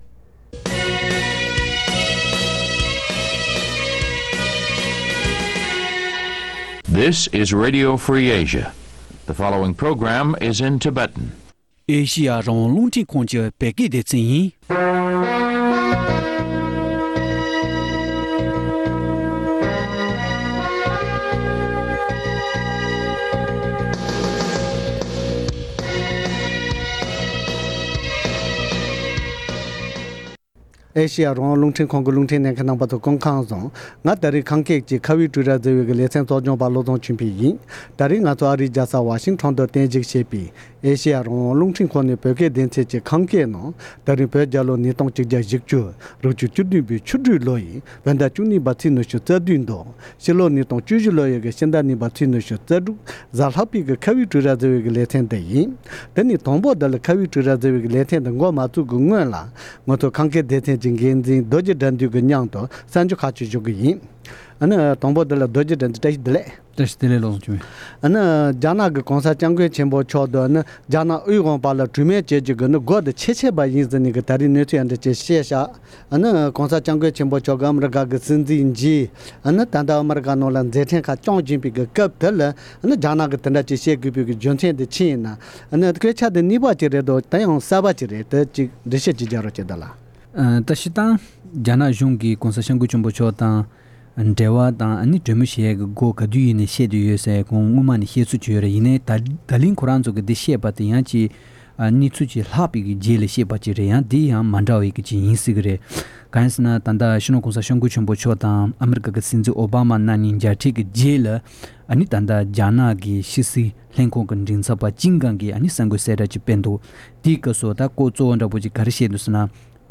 དེའི་སྐོར་འབྲེལ་ཡོད་དང་གླེང་མོལ་ཞུས་པ་ཞིག་གསན་རོགས་གནང་།།